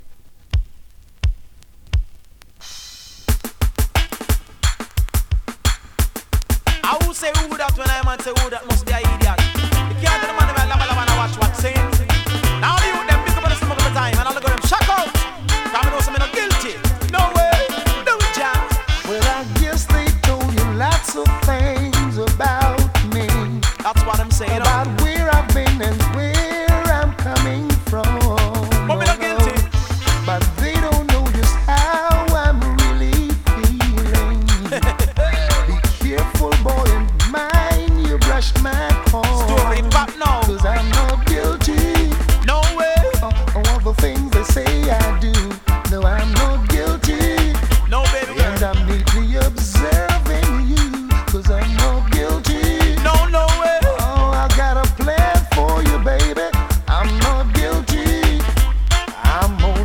DANCEHALL!!
スリキズ、ノイズ比較的少なめで